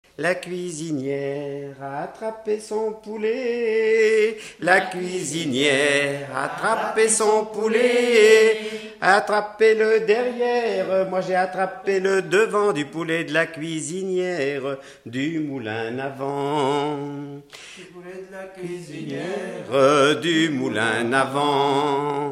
Usage d'après l'informateur circonstance : fiançaille, noce ;
Genre énumérative
Pièce musicale inédite